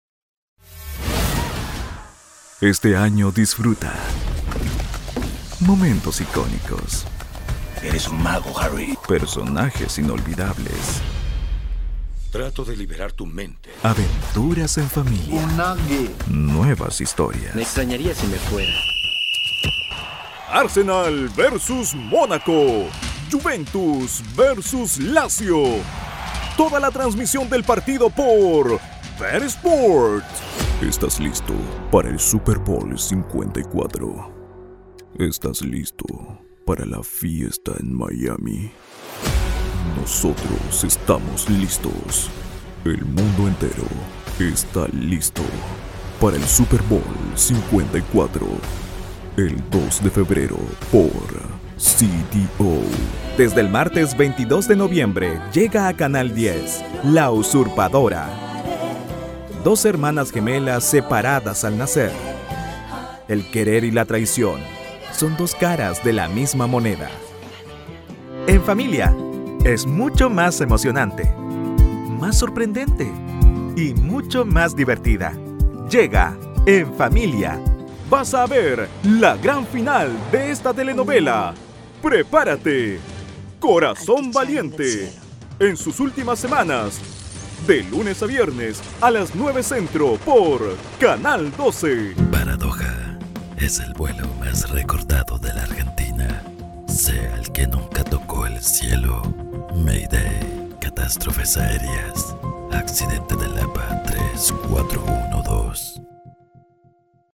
Microfone Neumann TLM 103
Estúdio doméstico
Jovem adulto